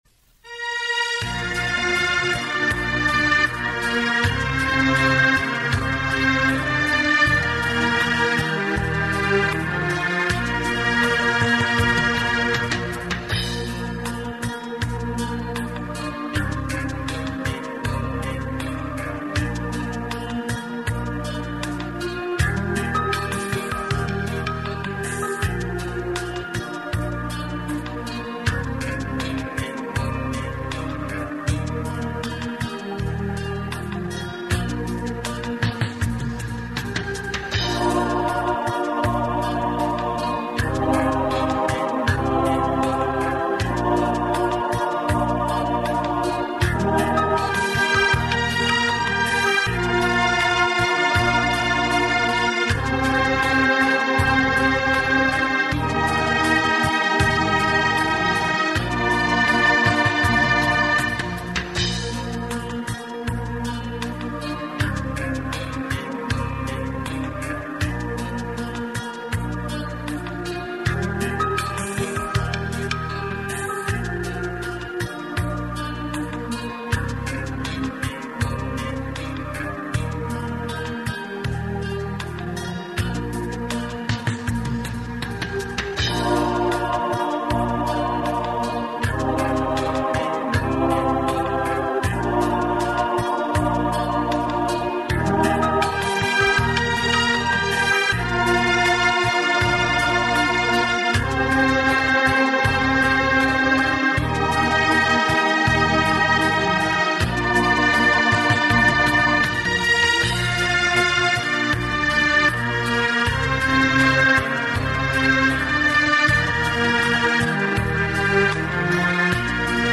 葫芦丝第六讲伴奏音乐